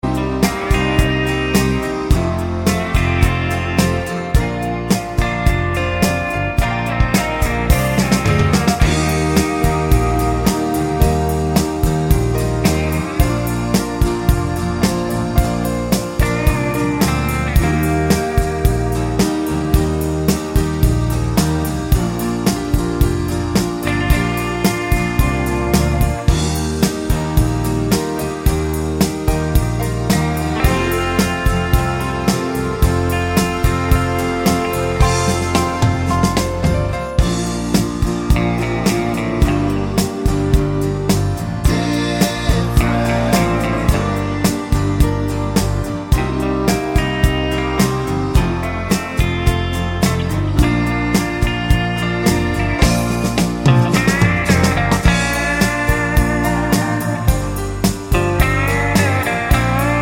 Outro Cut Down Pop (1980s) 3:03 Buy £1.50